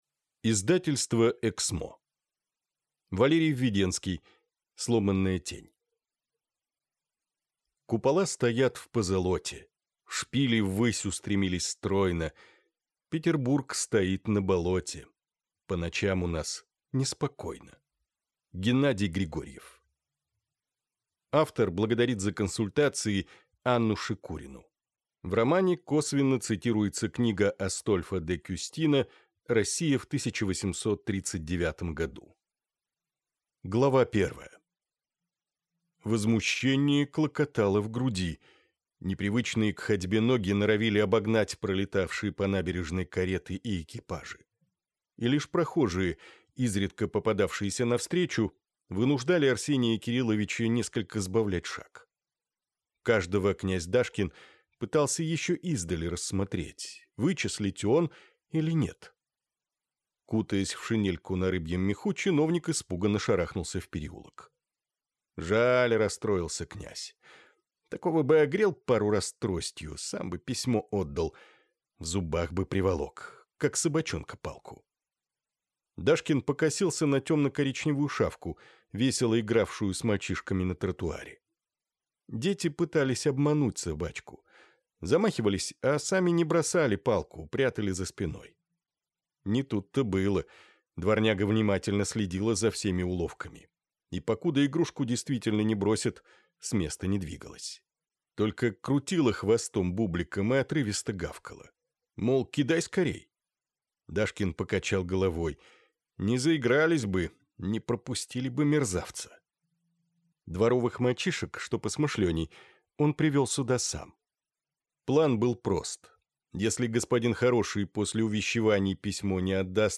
Аудиокнига Сломанная тень | Библиотека аудиокниг
Прослушать и бесплатно скачать фрагмент аудиокниги